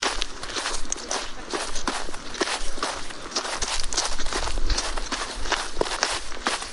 Footsteps Shuffle on Gravel and Snow
Nature
yt_FpX9gnqm3B0_footsteps_shuffle_on_gravel_and_snow.mp3